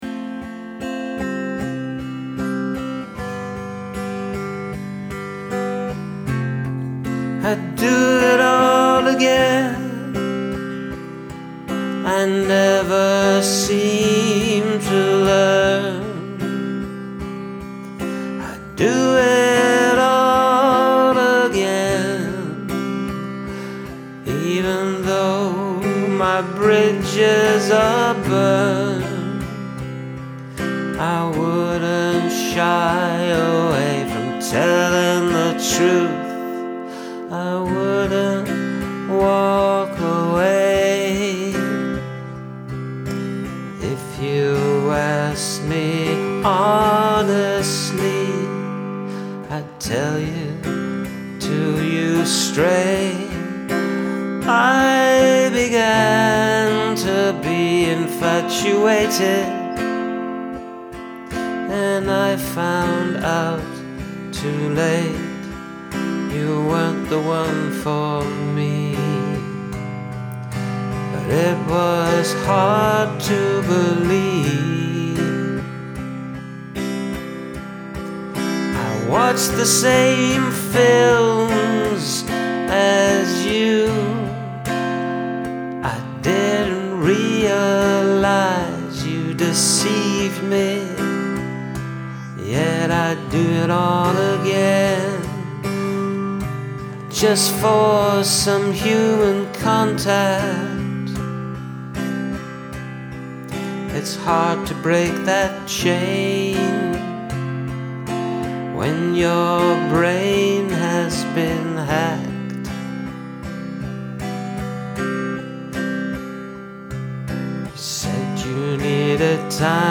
Wonderful singing and playing.